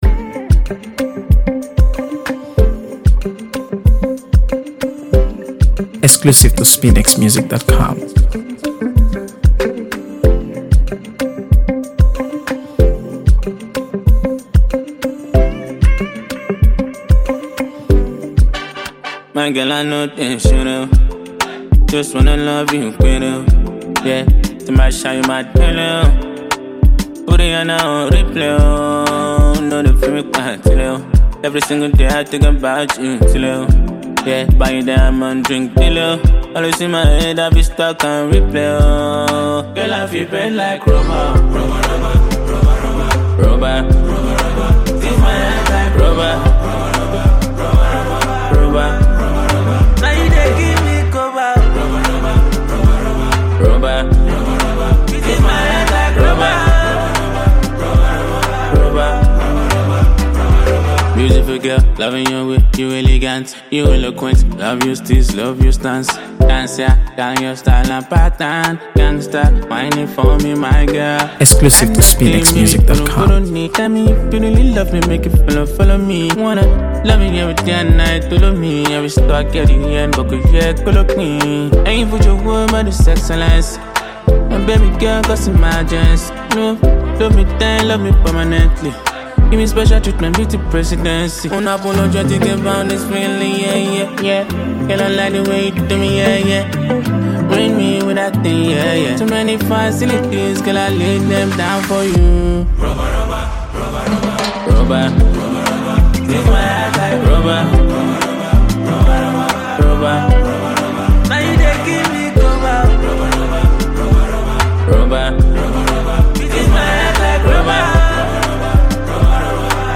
AfroBeats | AfroBeats songs
Smooth, well-crafted